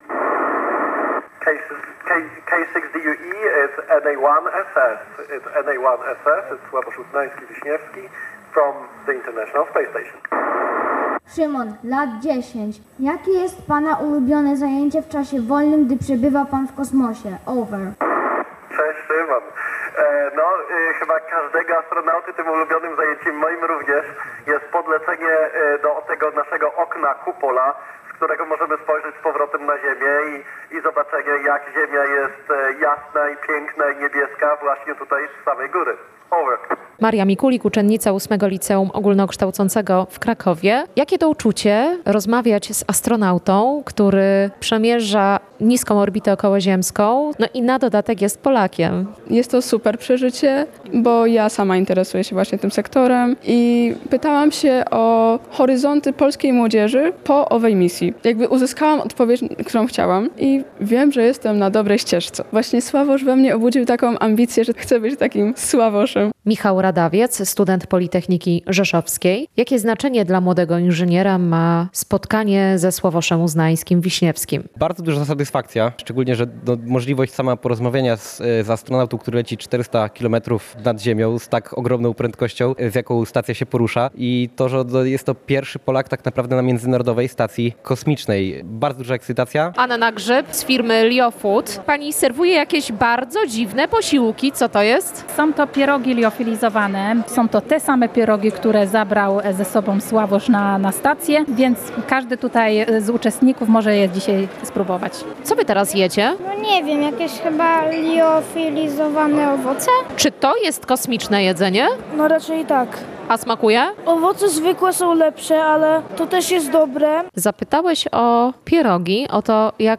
300 młodych osób w Rzeszowie wzięło udział w spotkaniu z dr. Sławoszem Uznańskim-Wiśniewskim. Polski astronauta przebywający na Międzynarodowej Stacji Kosmicznej przez 10 minut odpowiadał na ich pytania.
Kosmiczne spotkanie na Politechnice Rzeszowskiej